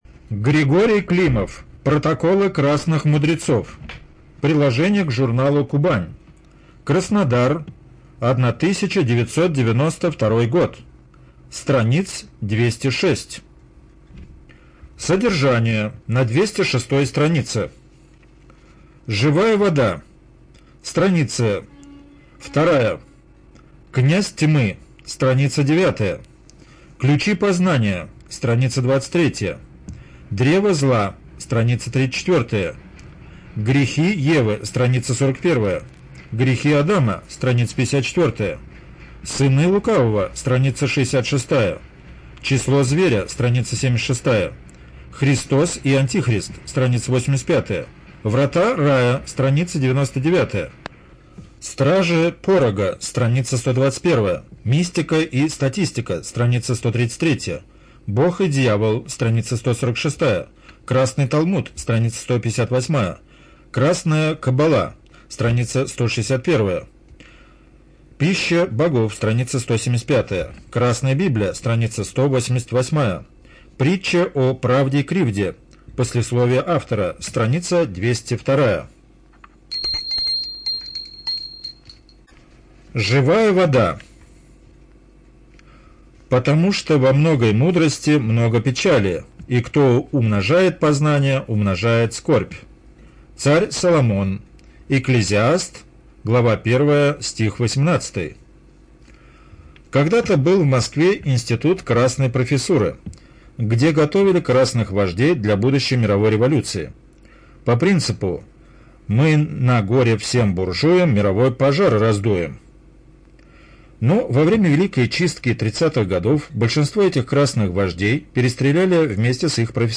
ЖанрПублицистика
Студия звукозаписиРоссийская государственная библиотека для слепых